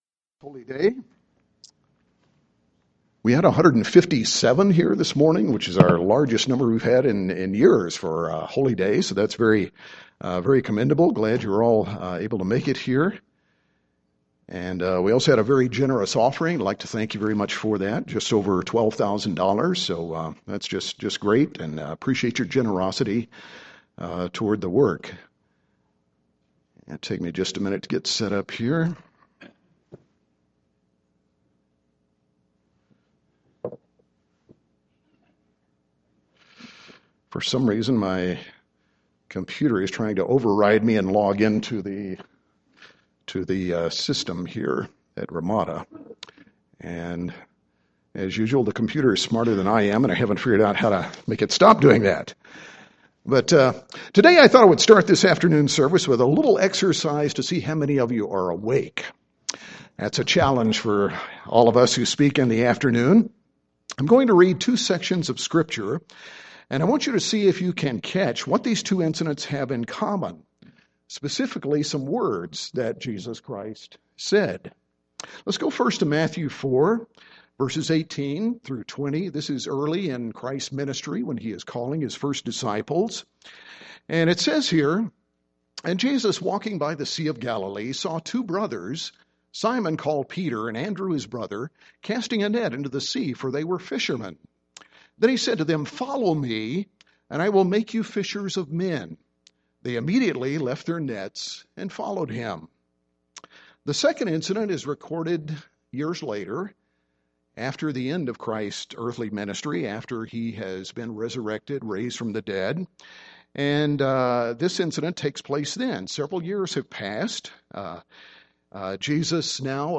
We can better understand by looking at the life of the apostle Peter, comparing his initial decision to follow Jesus Christ at the beginning of His ministry with Peter’s later decision to follow Christ after Jesus’ resurrection and ascension. Peter became a changed man, and those changes—particularly in three specific areas covered in this sermon—hold great lessons for us.